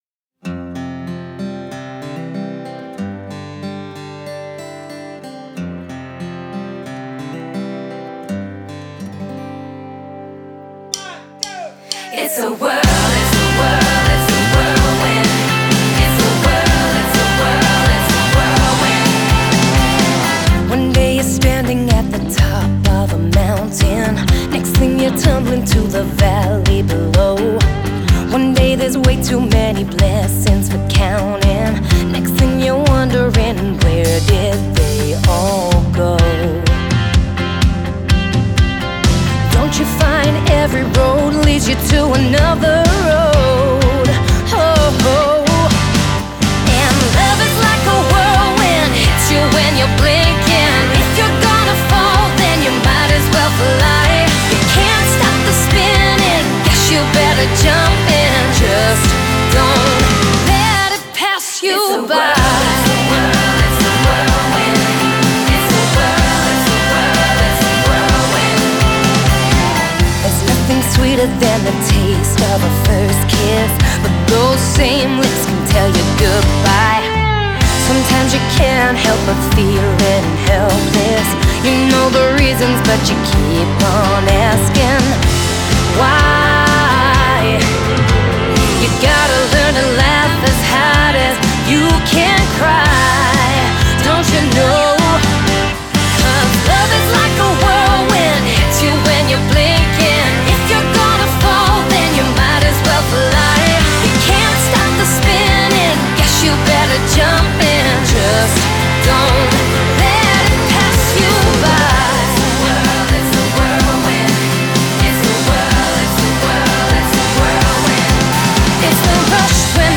Genre: Country, Folk, Female Vocalist